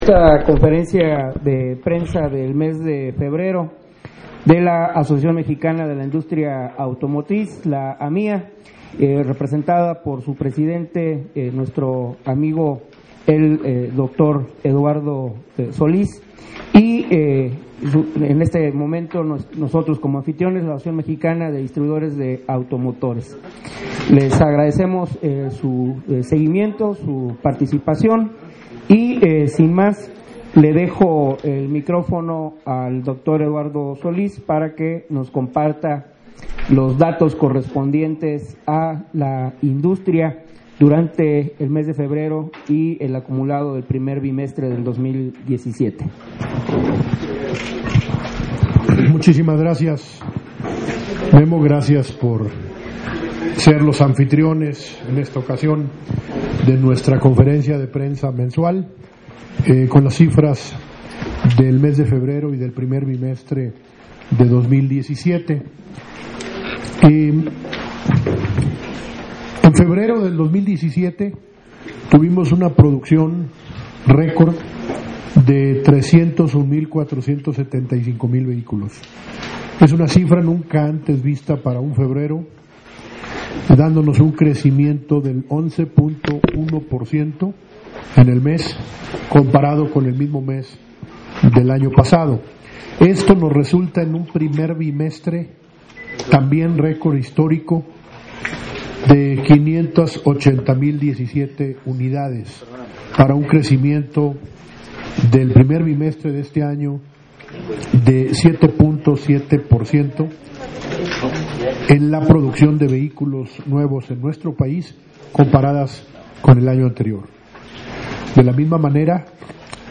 Descarga audio 1 de la conferencia